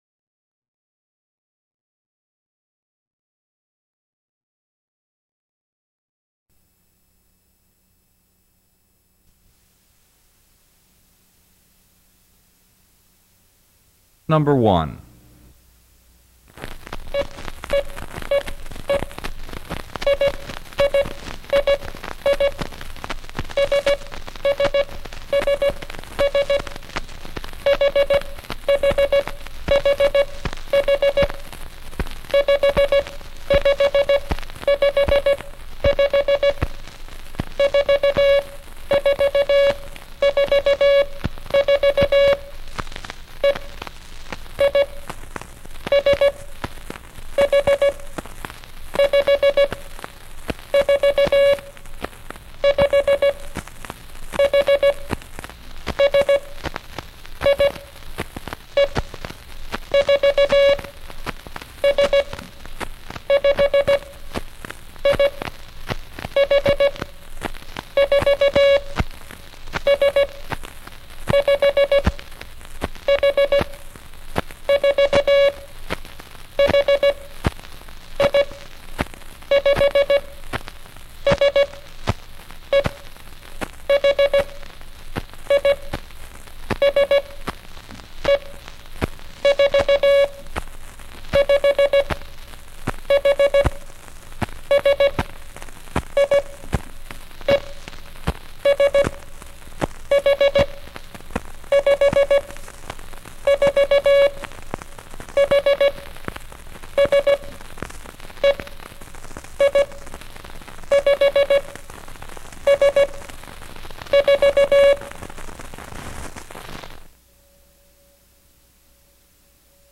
The audio code practice sessions were originally recorded on 78 RPM records. You may download the audio file in mp3 format, scratches and all, by clicking on: Morse-Code-Practice in 'mp3' format.
MorseCodePractice.mp3